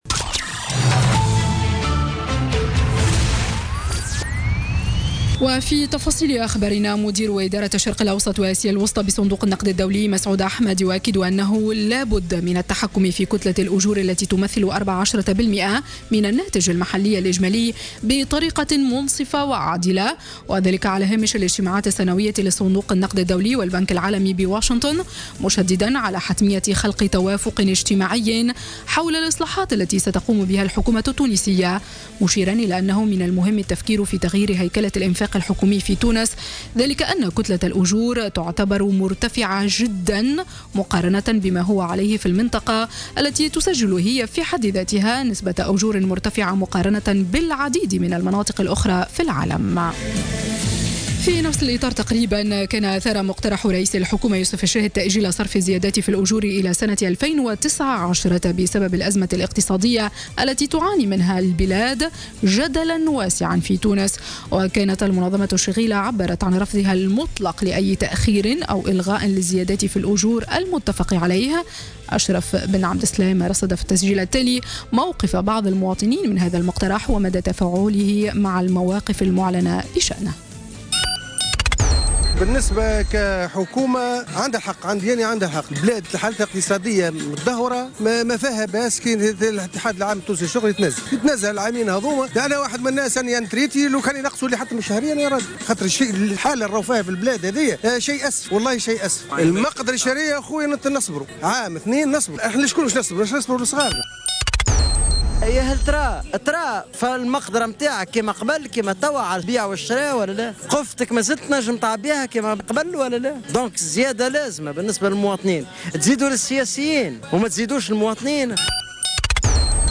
نشرة أخبار منتصف النهار ليوم السبت 8 أكتوبر 2016